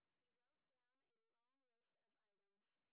sp11_street_snr10.wav